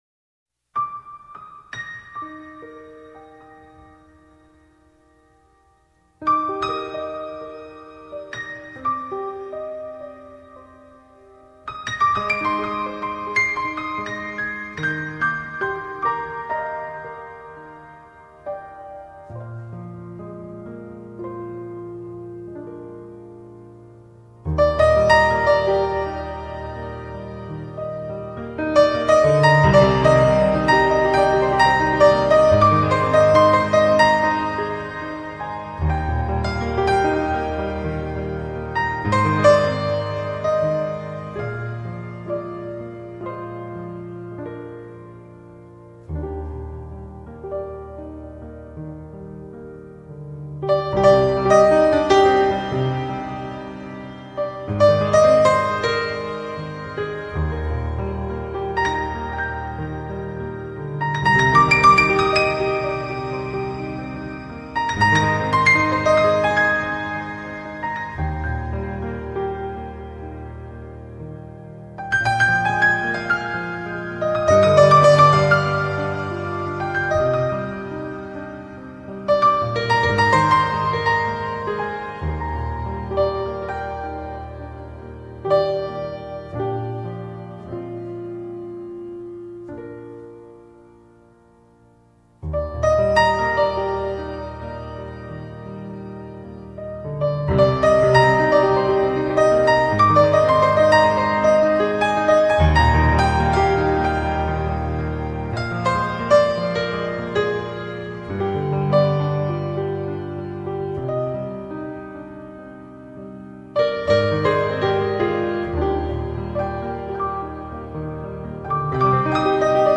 现在已成为世界着名的NEW AGE音乐作曲家和钢琴家。
12首优美钢琴曲撩动现代人的心弦，营造出泛着银晖的浪漫情境。